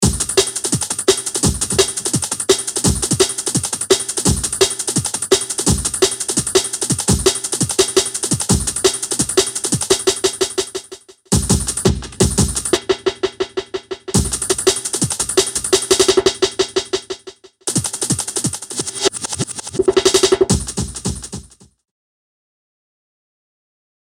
90’s breaks programming in Bitwig
I have always been a fan of 90’s jungle and drum and bass.
Find a suitable hit you want to add the pseudo delay to.
Finally to add a bit more old school grit, use the brilliant RX950 DA Converter and adjust the levels of crust to taste.
You can also modulate the filter on the RX950 using the VEL setting from the Expressions modulator to get something similar to s950 filter sound.